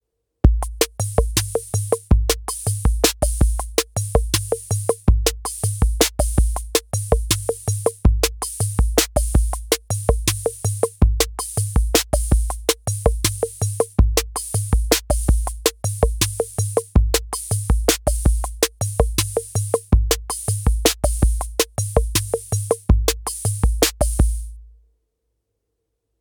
Qui un esempio di una traccia pulita registrata direttamente dall'uscita mono di Cyclone Analogic Beat Bot a 44,1 kHz 24 bit: